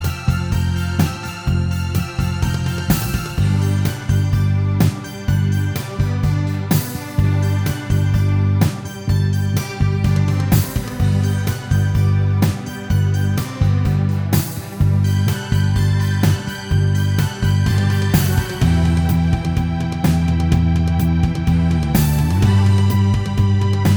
Minus Guitars Pop (1970s) 3:44 Buy £1.50